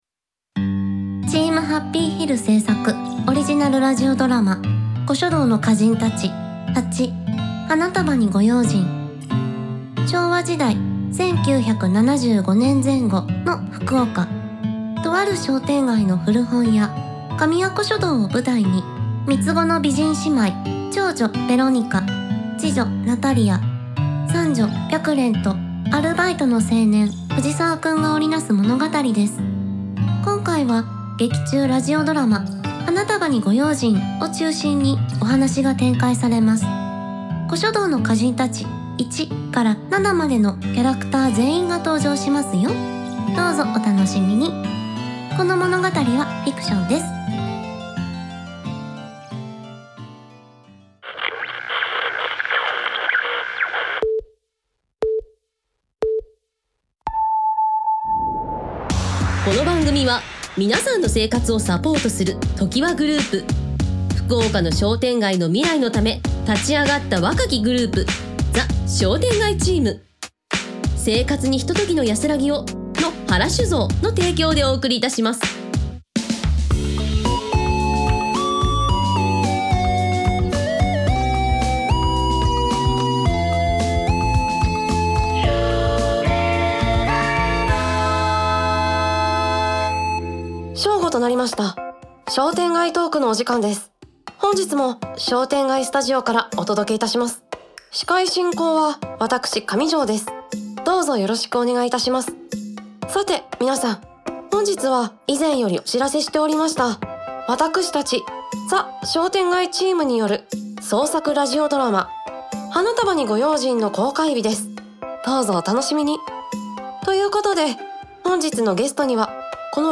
Team Happy Hill制作のオーディオドラマドラマ『私はベロニカ』シリーズ他ネットラジオ等、音声配信ブログです